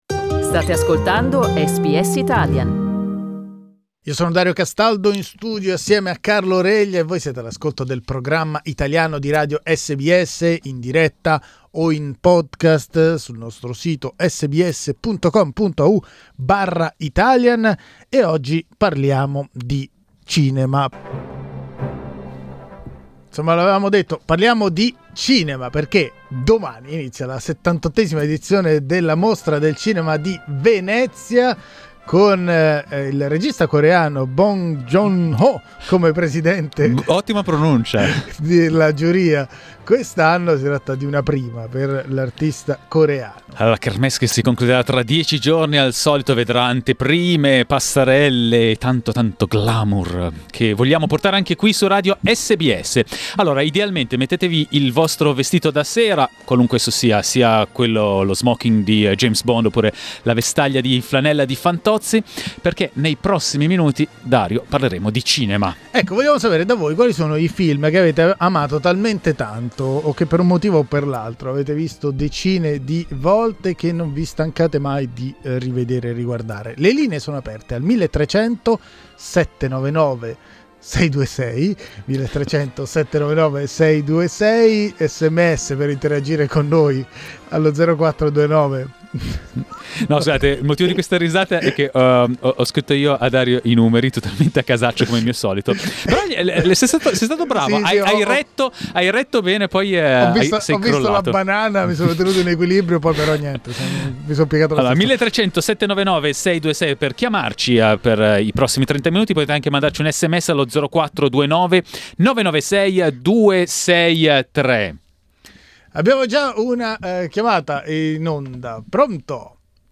Con la la Mostra Internazionale d'arte cinematografica di Venezia alle porte, abbiamo aperto le linee e abbiamo chiesto ai nostri ascoltatori di raccontarci quali sono i loro film preferiti.